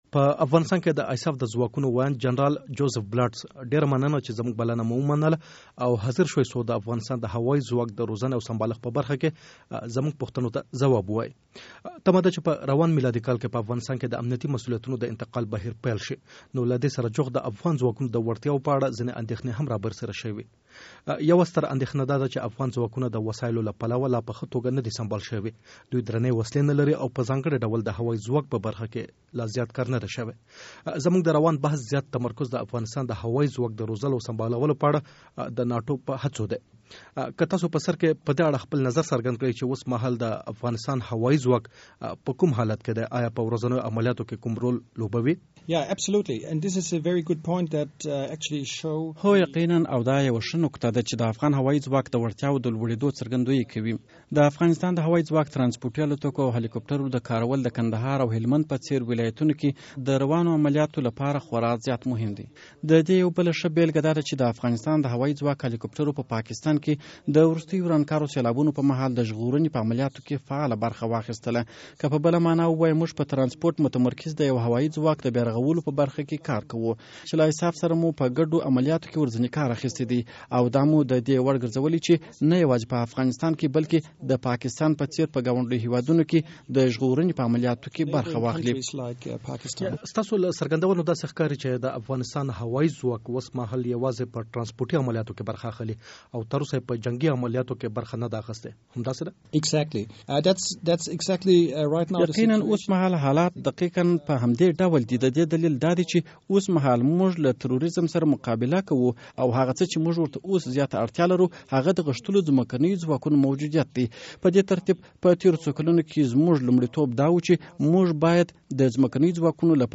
د ایساف د ځواکونو ویاند جنرال جوزیف بلاټس سره مرکه